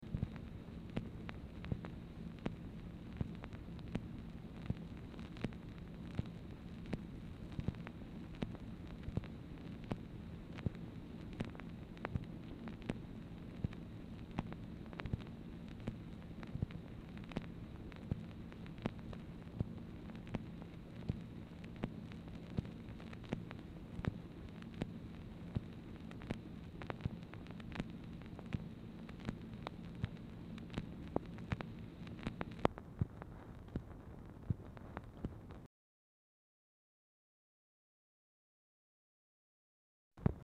Telephone conversation # 3058, sound recording, MACHINE NOISE, 4/17/1964, time unknown | Discover LBJ
Format Dictation belt